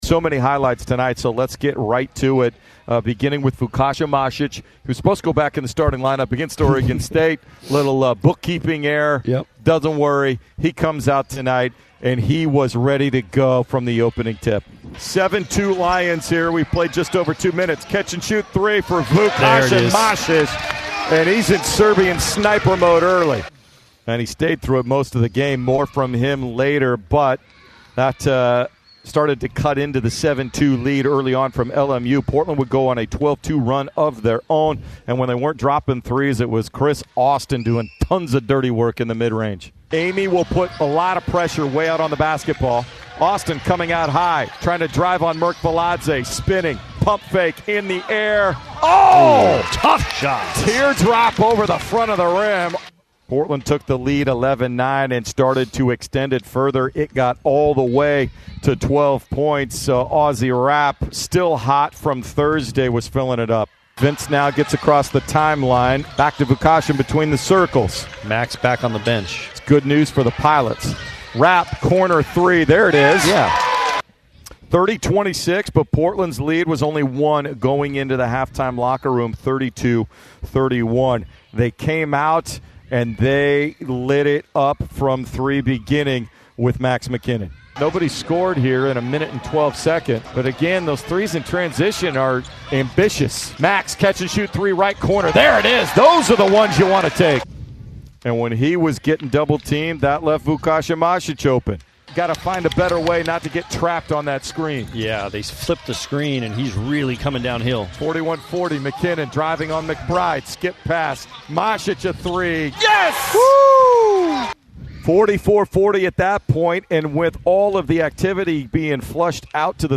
Radio Highlights vs. LMU